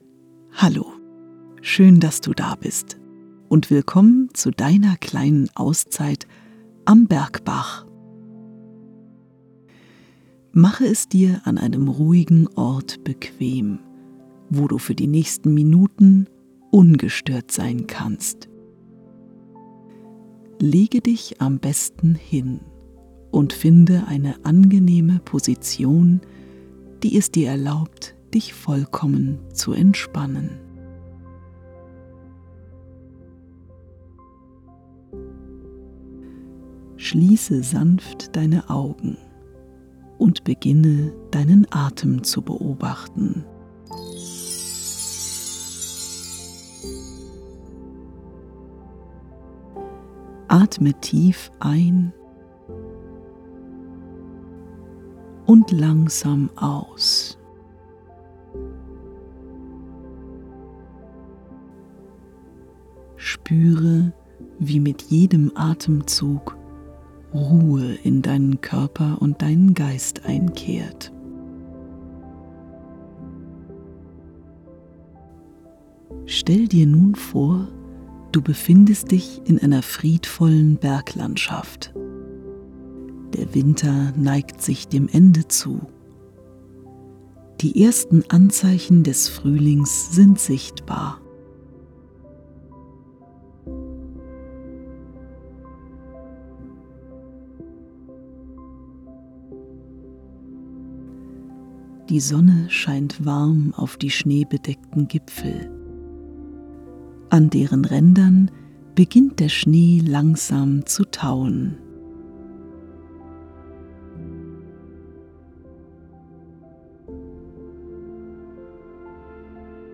Bergbach_bei_Schneeschmelze_-_Deine_kleine_Auszeit_-_Lunaris_Trauminsel.mp3